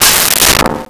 Metal Strike 04
Metal Strike 04.wav